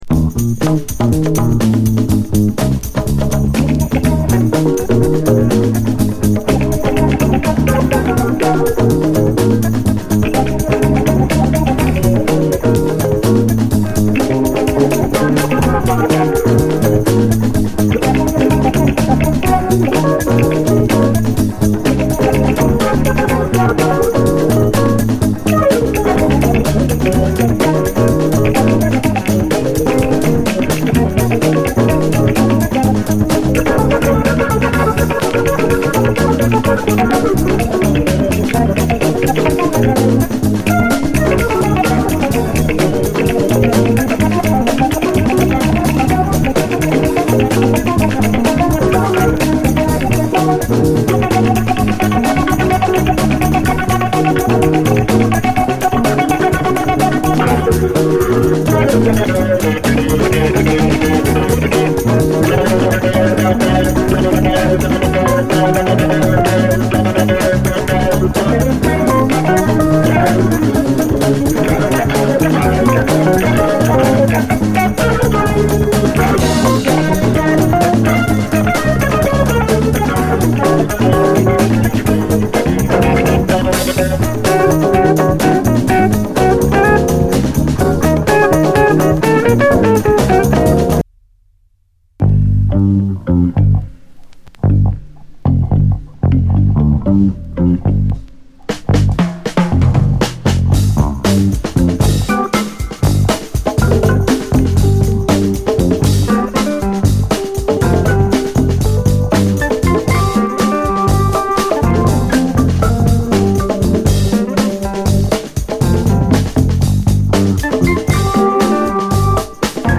JAZZ FUNK / SOUL JAZZ, JAZZ
ファットさ全開の重量級ジャズ・ファンク〜レアグルーヴ！